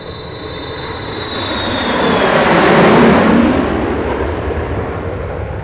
ShipPass.mp3